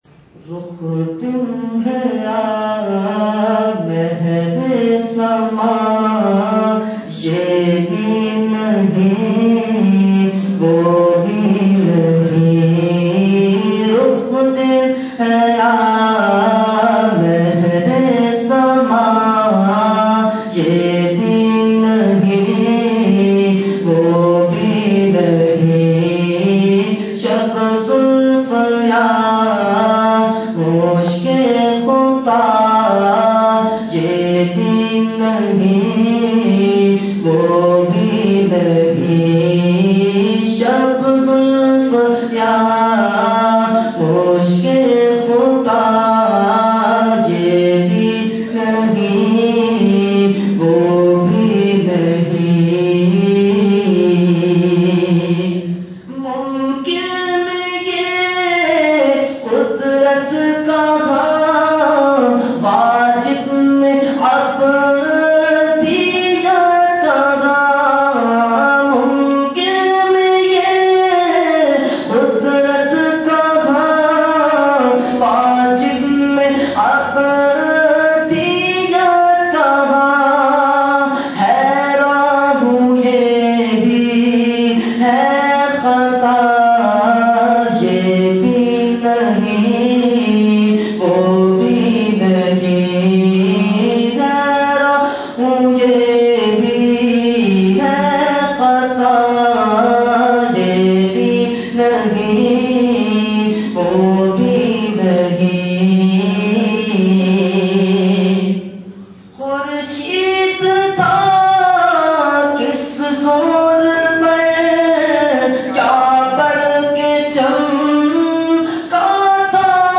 Category : Naat | Language : UrduEvent : Mehfil Milad Akhund Masjid Kharader 10 January 2014
02-Naat-Rukh Din He Ya.mp3